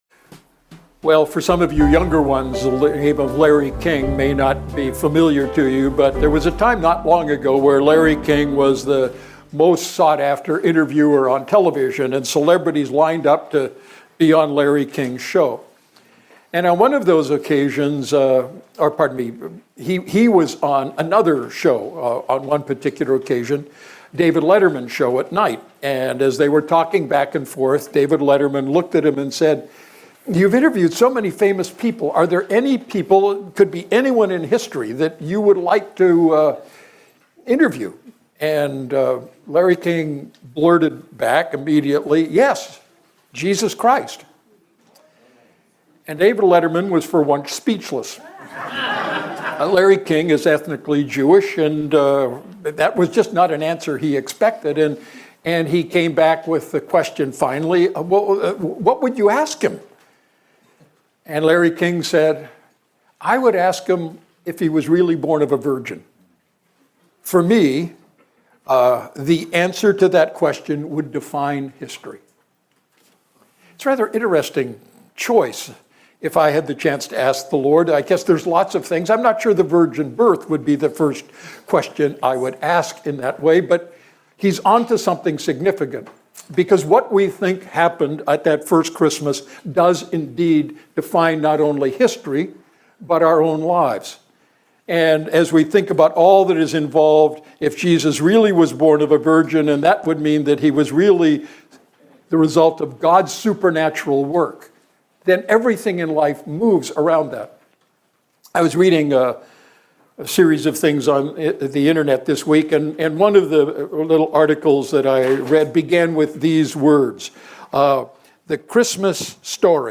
Sermon Archive, Redeemer Fellowship